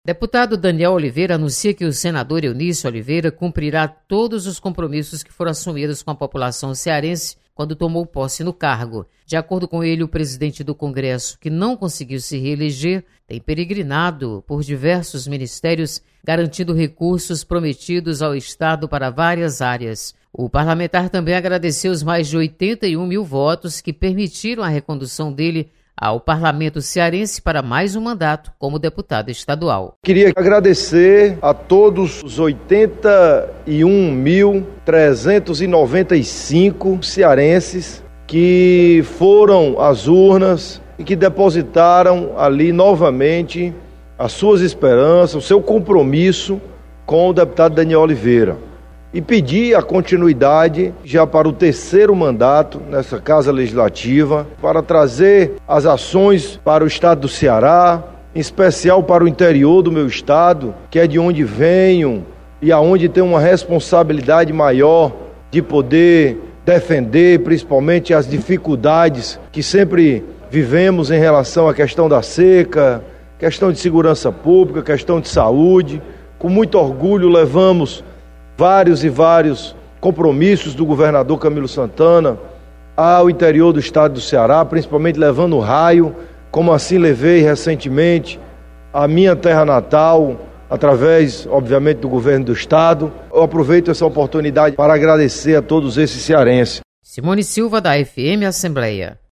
Deputado Danniel Oliveira destaca compromissos do senador Eunício Oliveira.